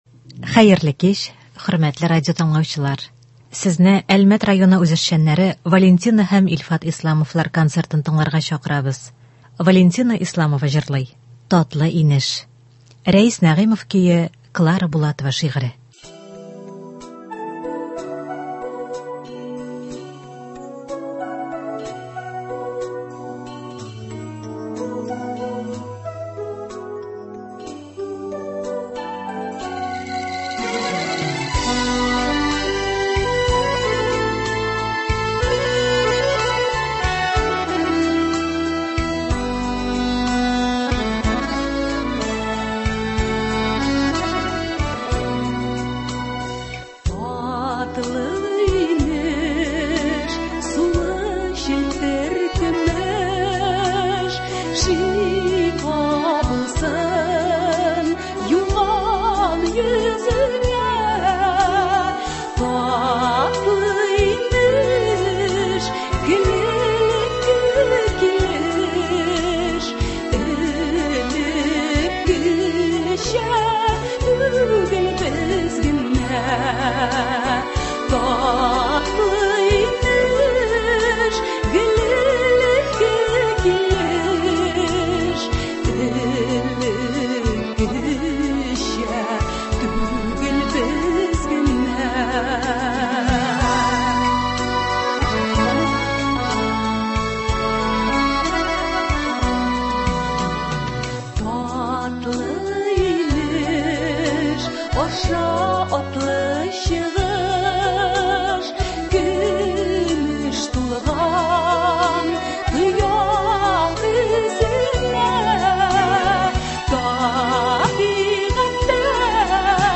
Концерт (24.01.22)